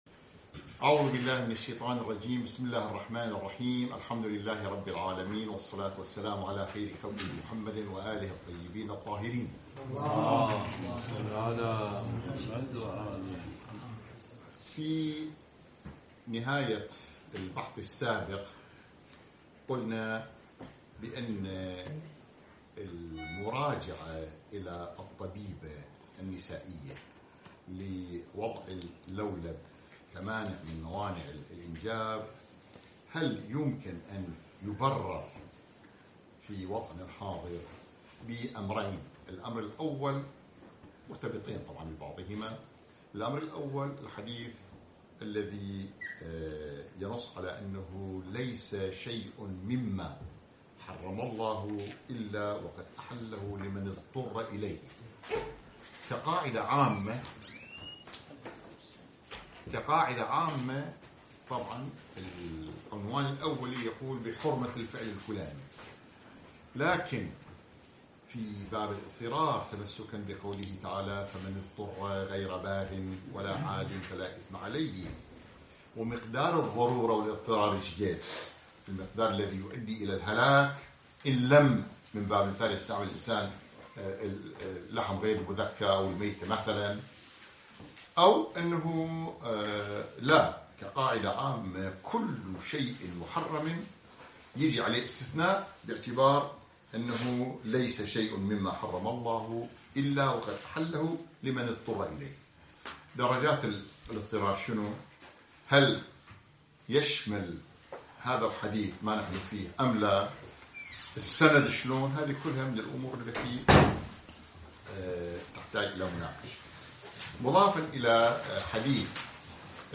تنظيم النسل 12 دروس الفقه المحاضرة الثانية عشر